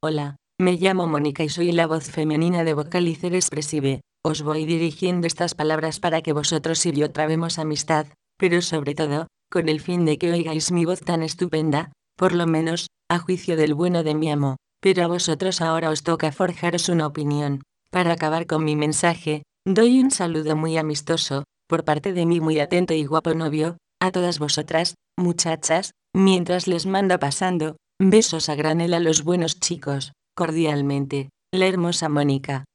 Texte de démonstration lu par Monica, voix féminine espagnole de Vocalizer Expressive
Écouter la démonstration d'Monica, voix féminine espagnole de Vocalizer Expressive